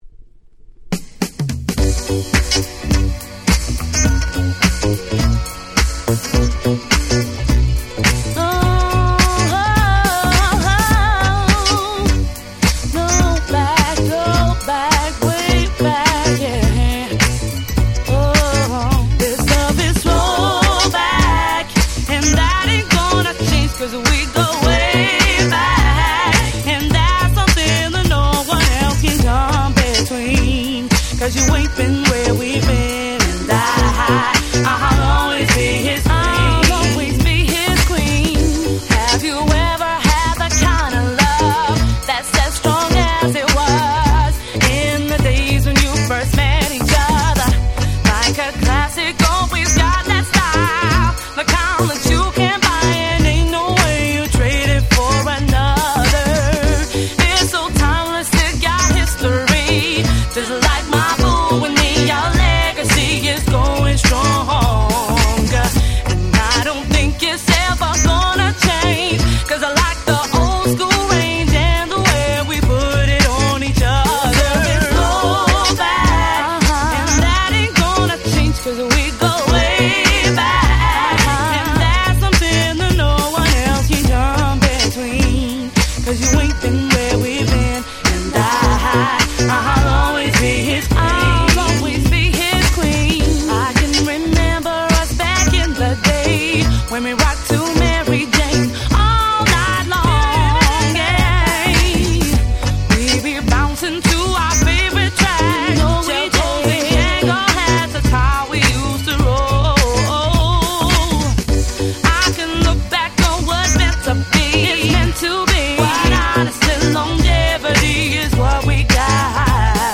05' Very Nice R&B / Neo Soul !!
00's ネオソウル キャッチー系